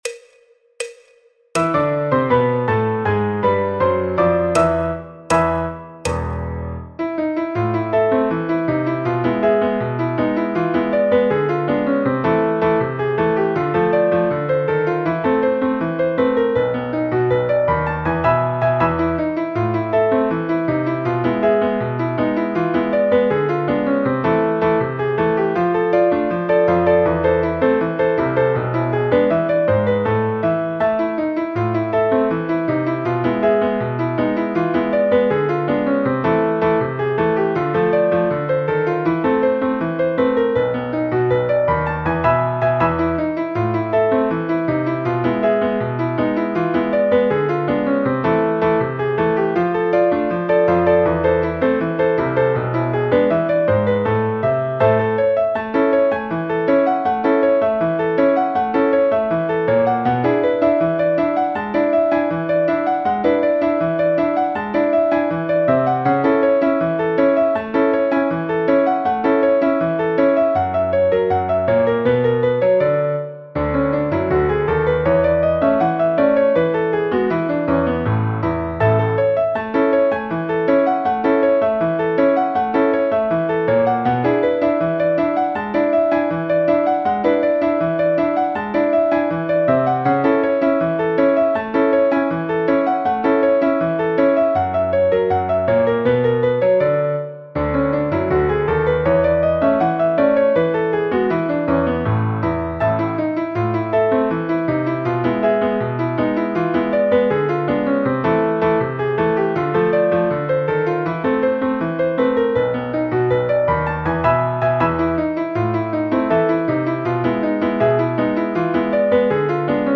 Partitura PDF de Piano en La menor y La Mayor
Choro, Jazz, Popular/Tradicional